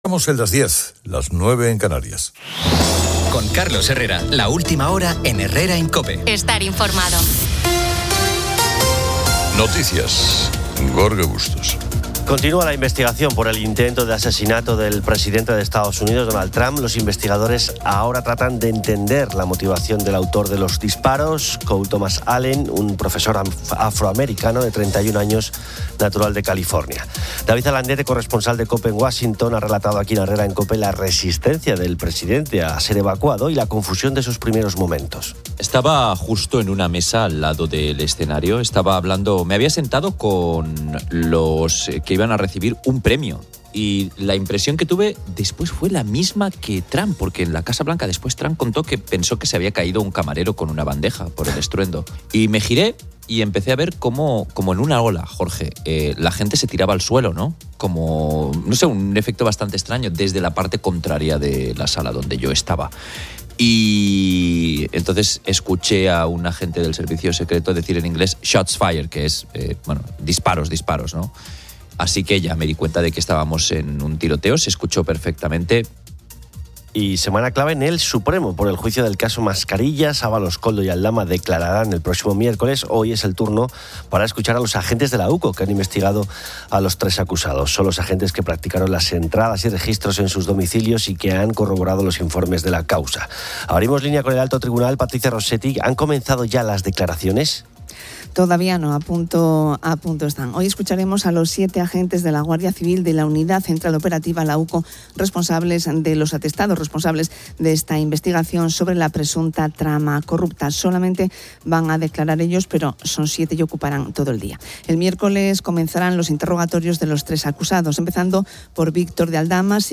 Un tema principal es el debate sobre las actividades extraescolares, donde los oyentes comparten experiencias con robótica, natación sincronizada o flag football, y se reflexiona sobre la importancia del aburrimiento para la creatividad infantil.